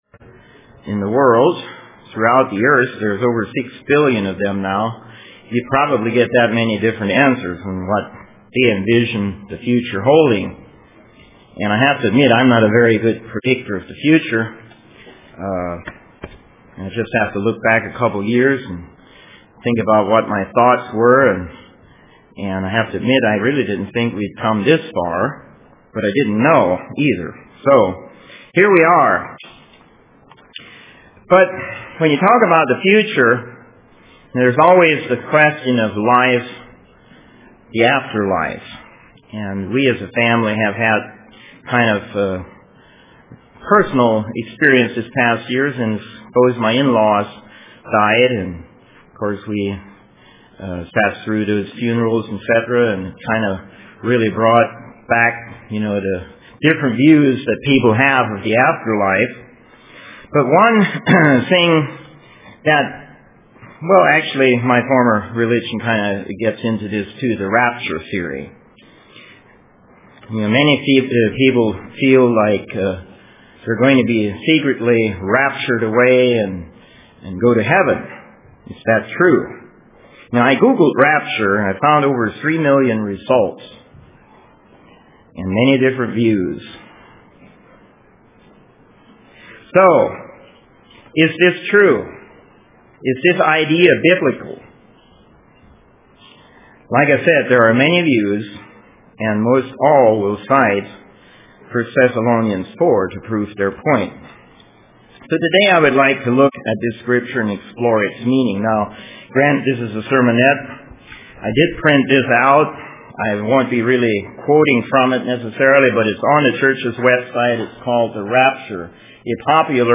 Print The Rapture UCG Sermon Studying the bible?